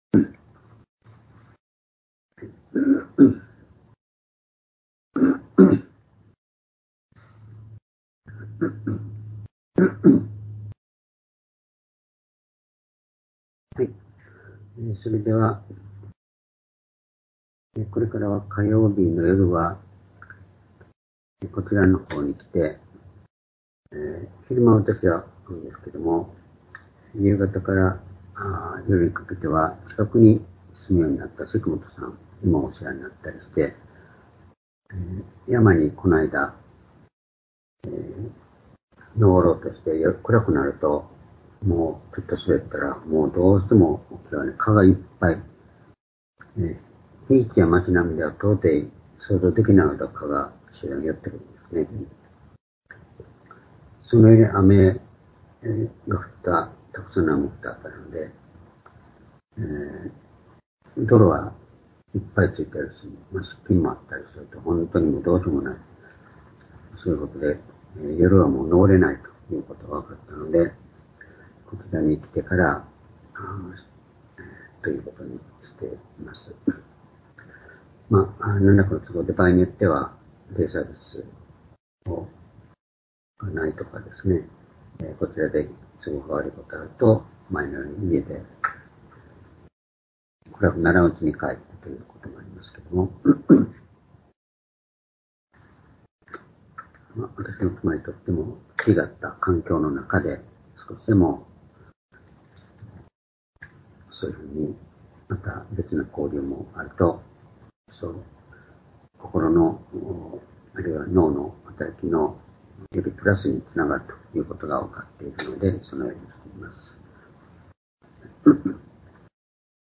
（主日・夕拝）礼拝日時 ２０２３年9月19日（夕拝） 聖書講話箇所 「この世界の根本問題―悪の力と神の力」 詩編３７の１１－２２ ※視聴できない場合は をクリックしてください。